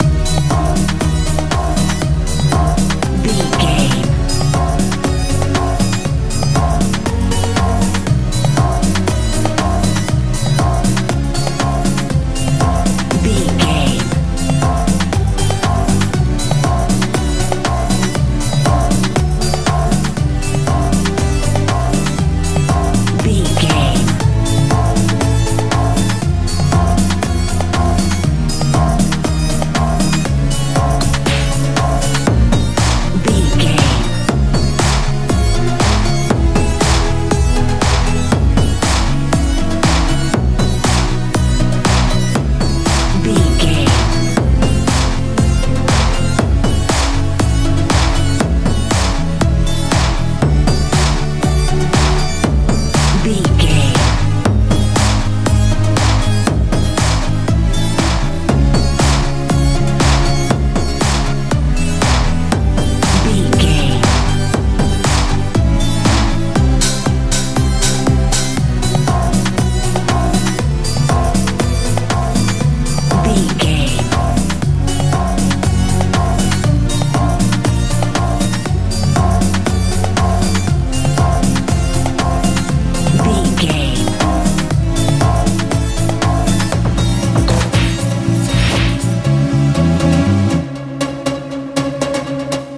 modern dance
Aeolian/Minor
hopeful
joyful
bass guitar
synthesiser
drums
80s
90s
suspense
strange